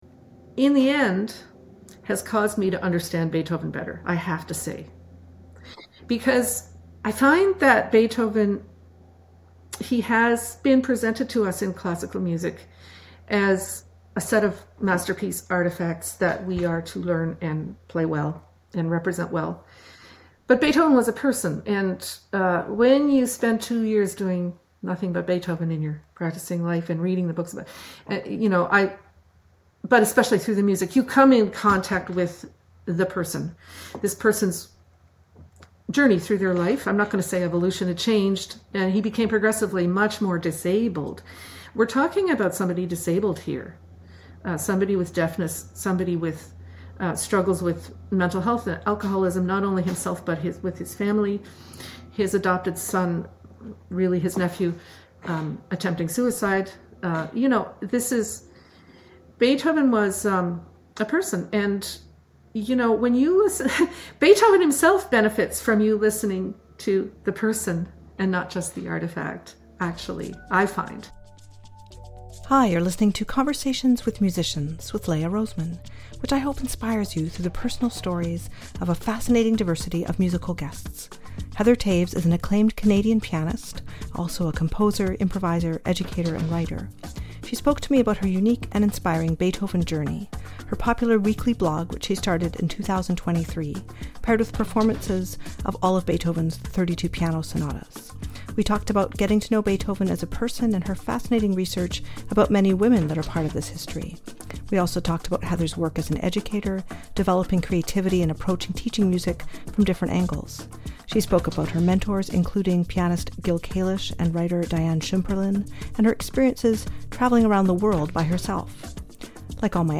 The episode ends with some improvisation and some great parenting advice!